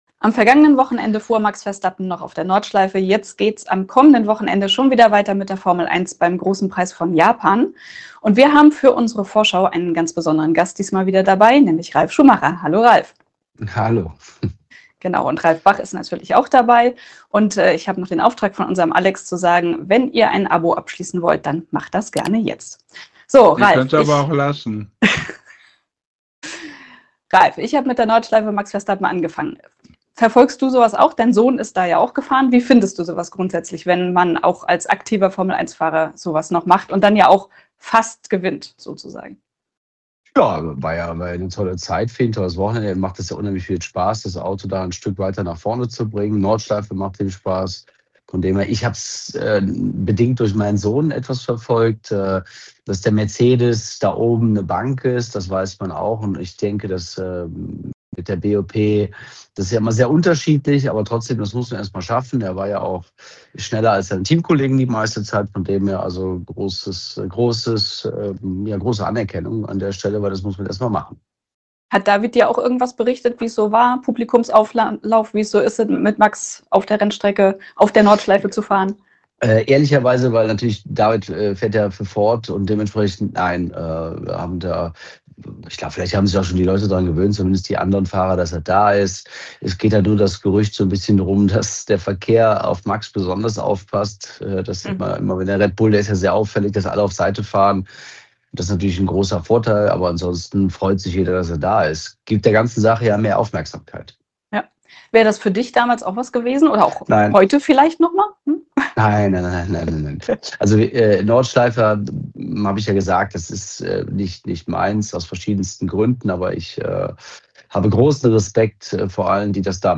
Exklusiv-Interview mit Ralf Schumacher vor dem anstehenden Grand Prix von Japan.